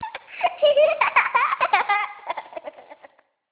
Cackle.amr